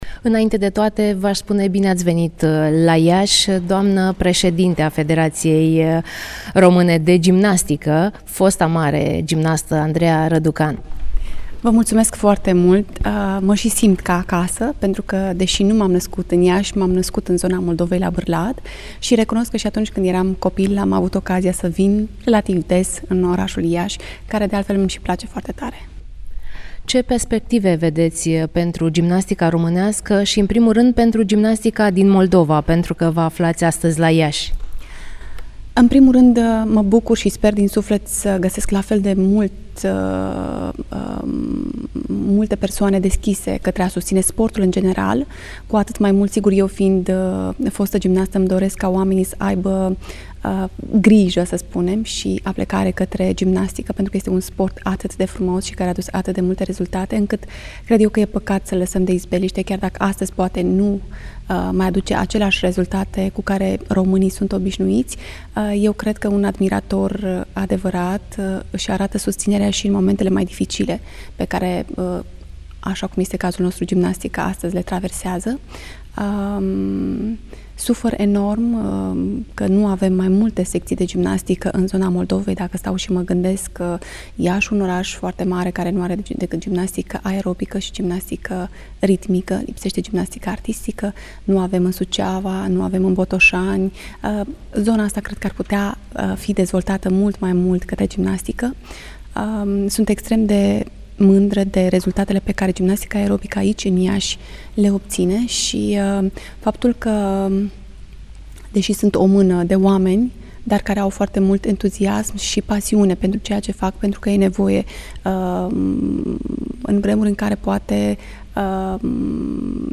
Într-un interviu acordat în exclusivitate colegei noastre